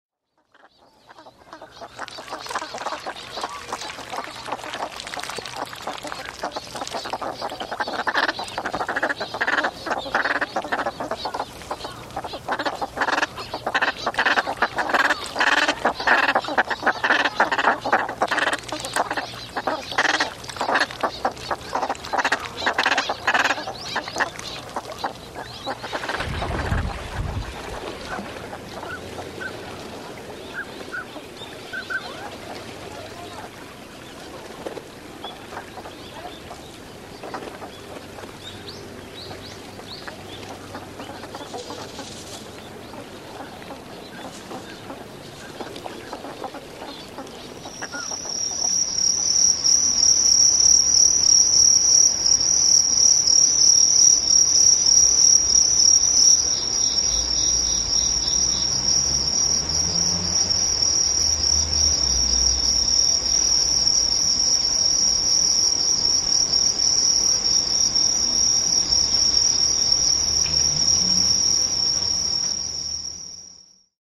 field recording album
The CD includes an expanded version of ‘Cricket Voice’ which did not appear on the original IDEA LP release.